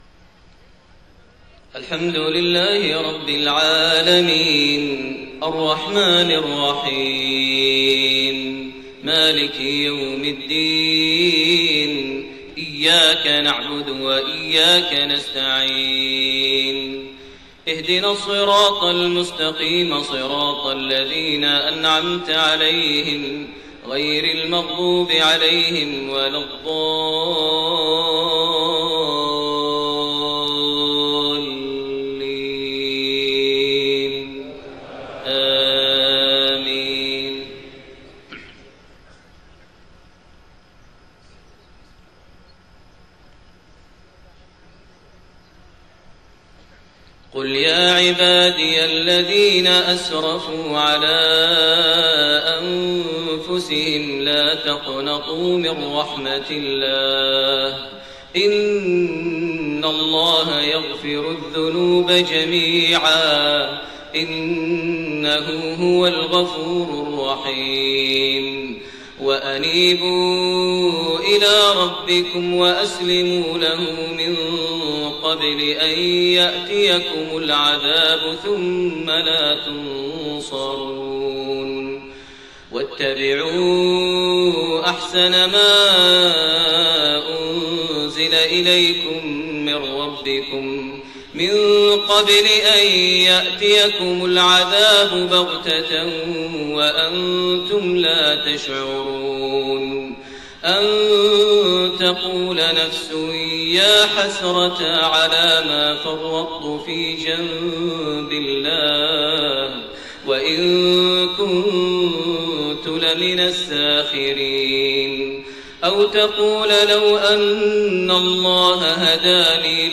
صلاة الفجر 2-4-1431 من سورة الزمر53-75 > 1431 هـ > الفروض - تلاوات ماهر المعيقلي